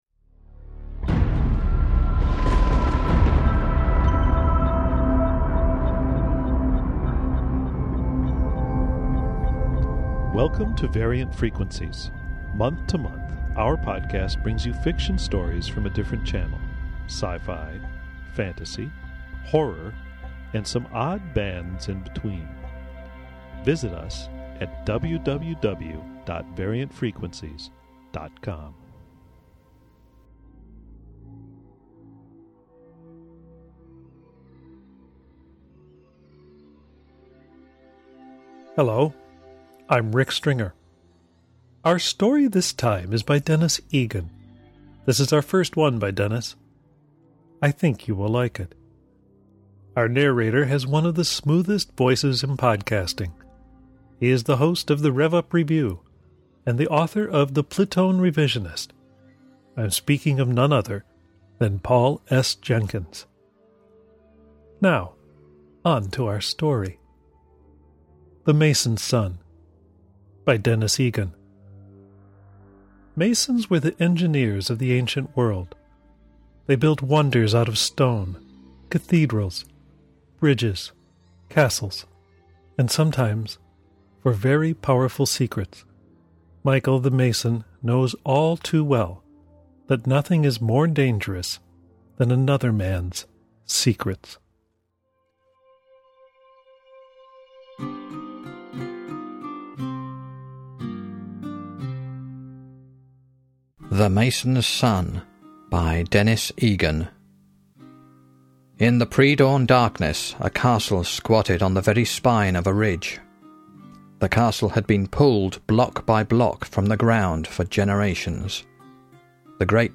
Audio Fiction